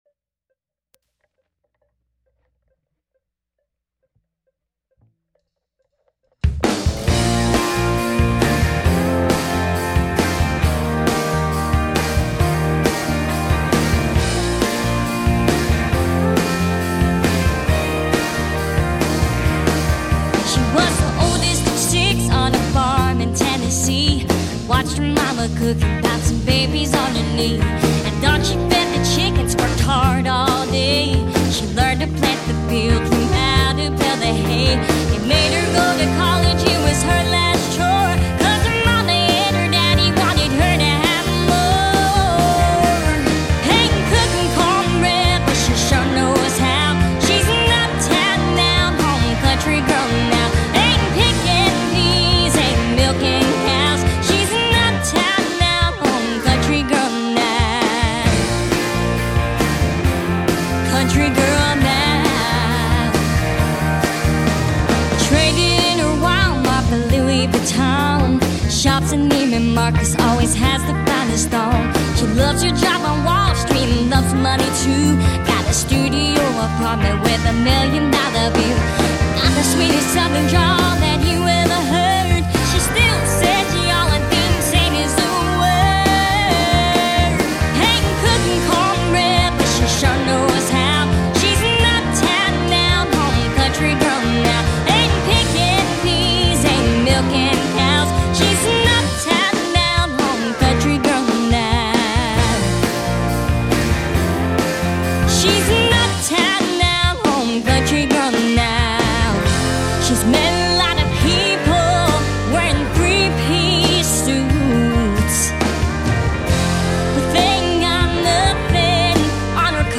"Country Girl." (country)